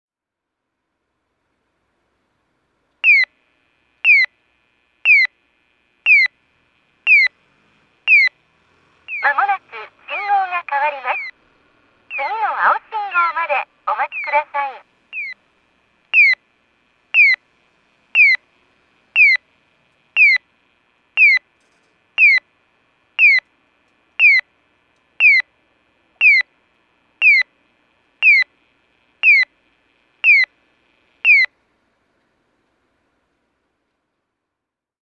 原バス停先(大分県別府市)の音響信号を紹介しています。